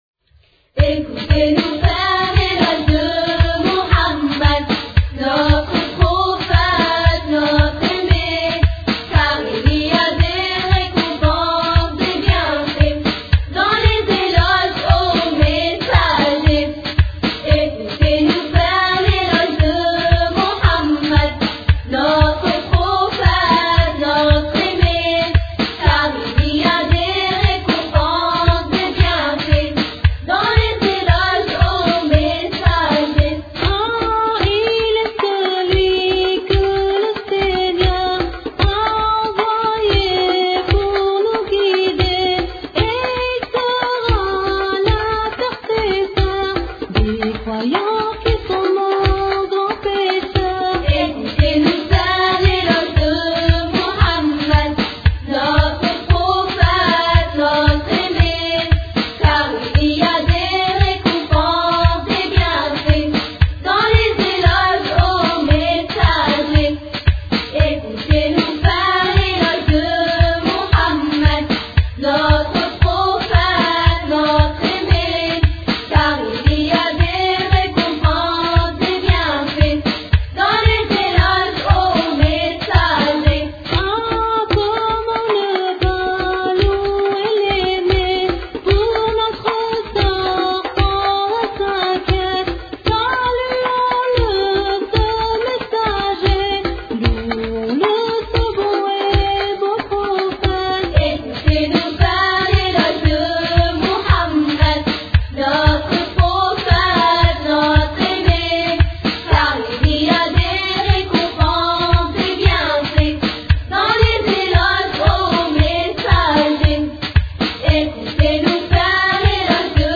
Chant réalisé par des soeurs
Attention ! chants de soeurs spécial Aïd !